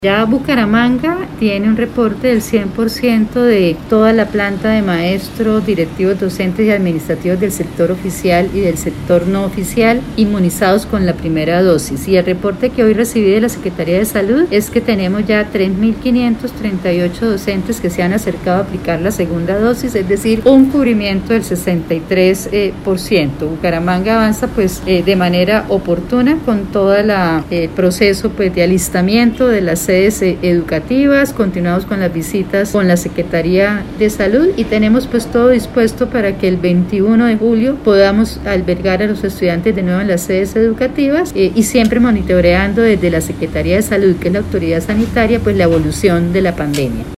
Audio: Ana Leonor Rueda, secretaria de Educación